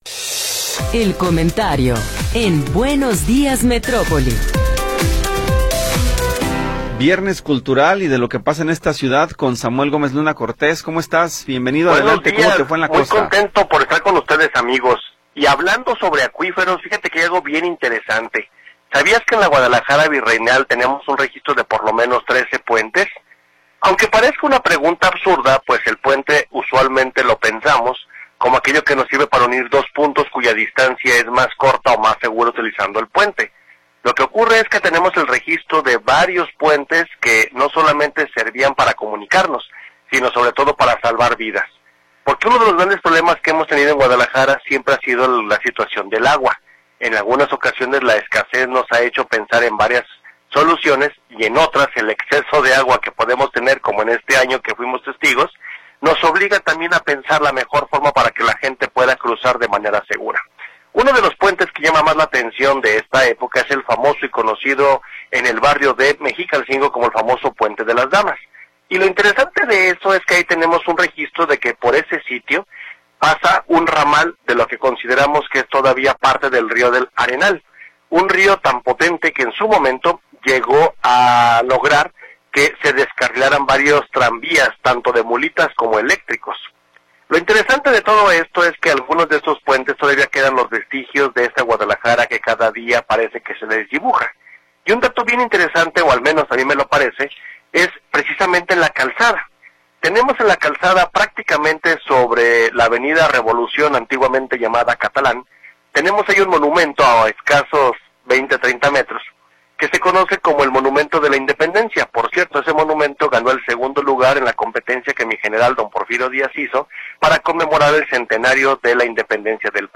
Comentario de Samuel Gómez Luna Cortés
Samuel Gómez Luna Cortés, cronista de Guadalajara, nos habla sobre los puentes de Guadalajara.